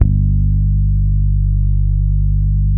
NO FRET .1-L.wav